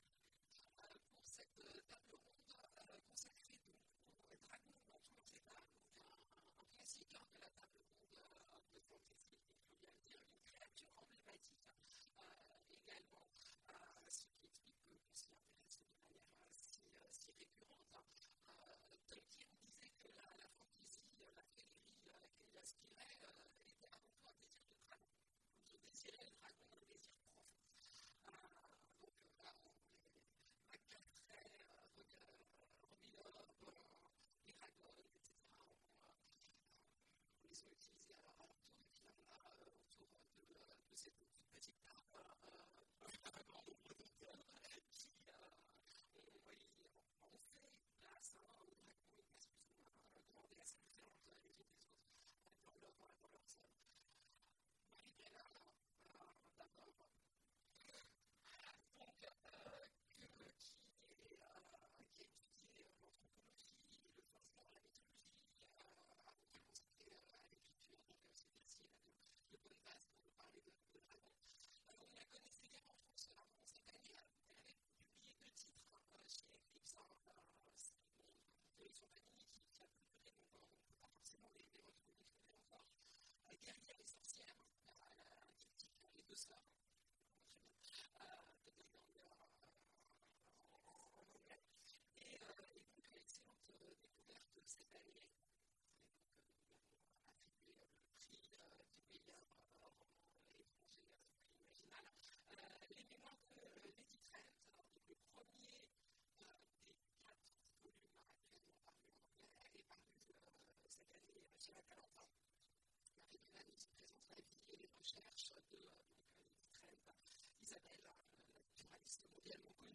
Imaginales 2016 : Conférence Dragons…